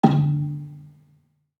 Gamelan Sound Bank
Gambang-D2-f.wav